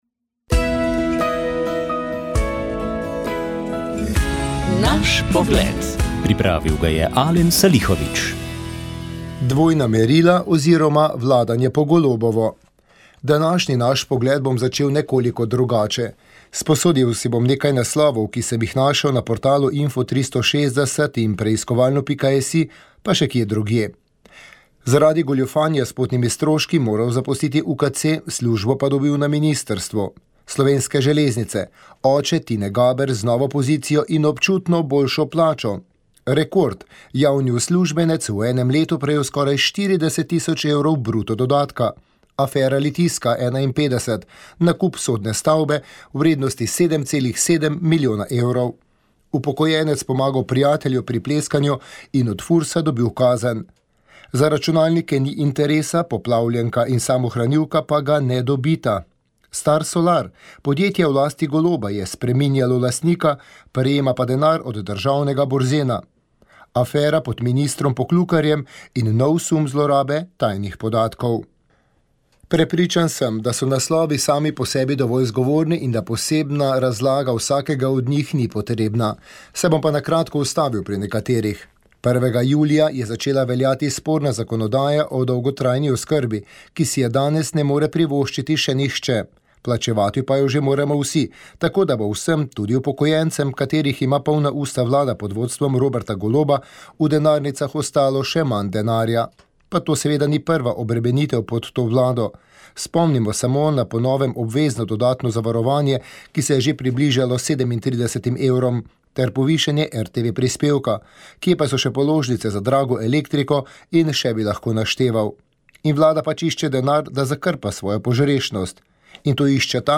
oddajo o ljudski glasbi
orgličar
pohorski godci s Tajne s starimi ohcetnimi vižami
tamburaška skupina